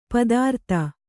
♪ padārta